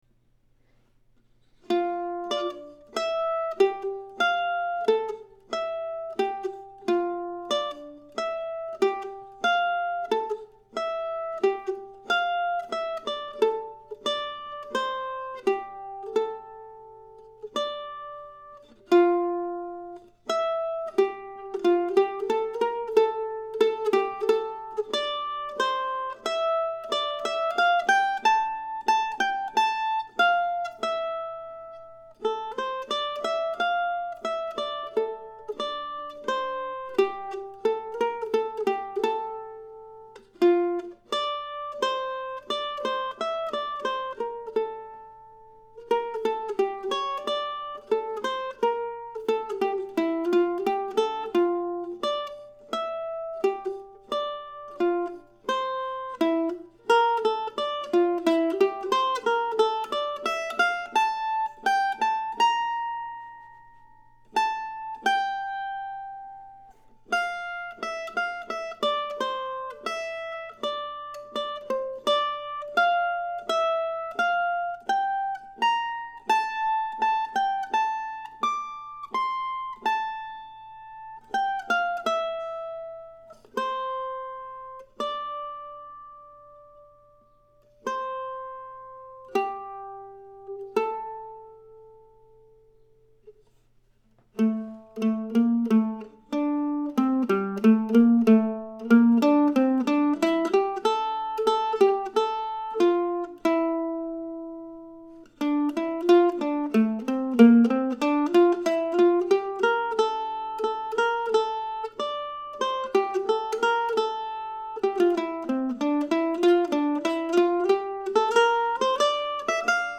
Today's piece, recorded this morning, began with the title May 30, 2007.
It's a comfortable piece to play on the mandolin and, if you choose, can be a good exercise in playing in the second position with brief movements up to third position.
WhatHearsFollows-solo.mp3